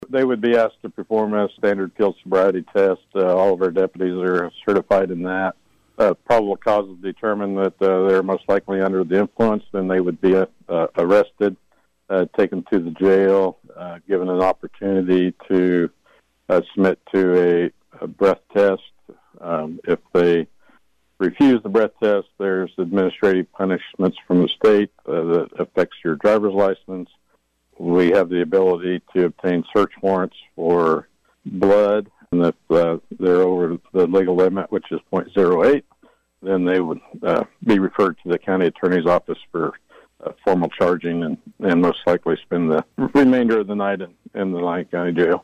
Lyon County Sheriff Jeff Cope joined KVOE’s Morning Show Monday to discuss the effort and explained exactly what will happen if someone is pulled over under suspicion of DUI.